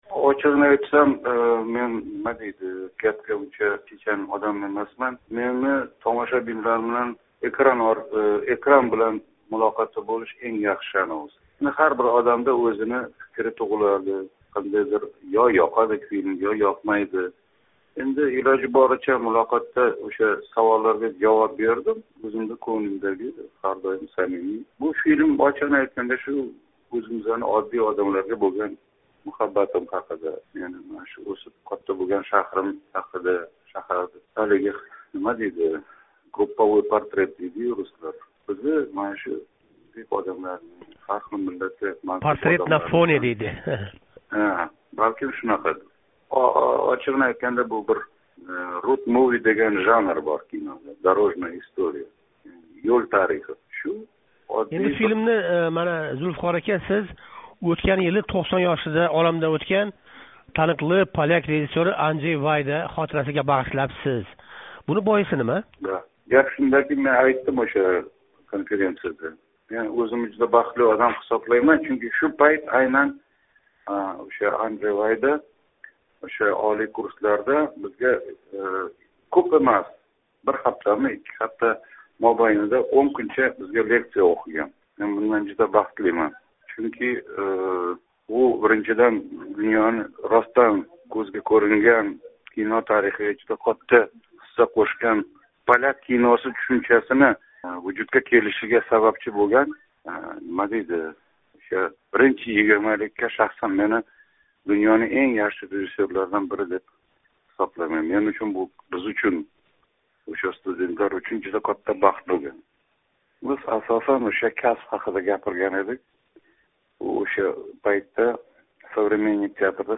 Зулфиқор Мусоқов билан суҳбат